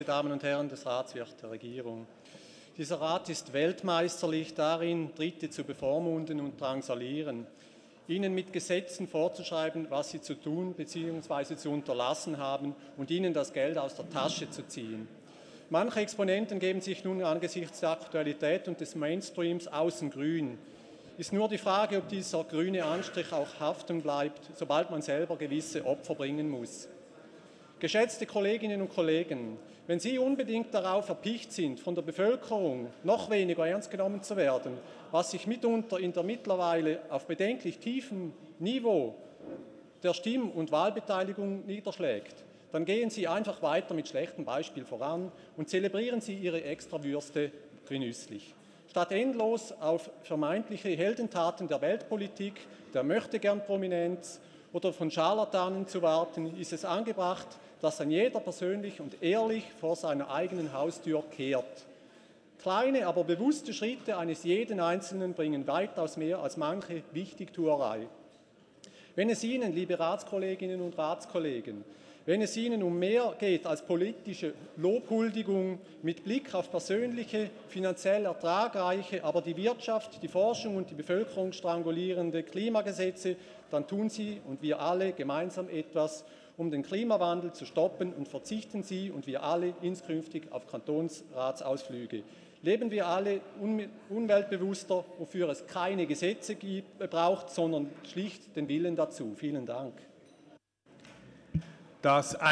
13.6.2019Wortmeldung
Session des Kantonsrates vom 11. bis 13. Juni 2019